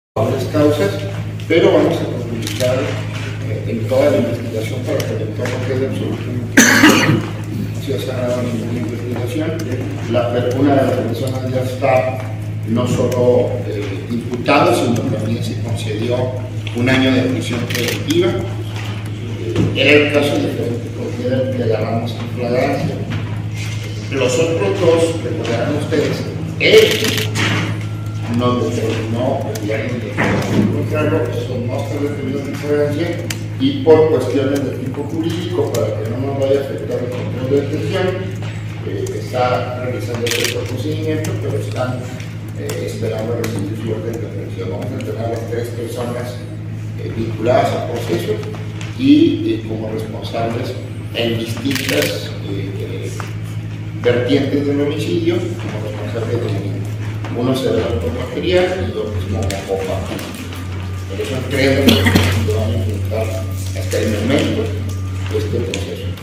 AUDIO: CÉSAR JÁUREGUI MORENO, FISCAL GENERAL DEL ESTADO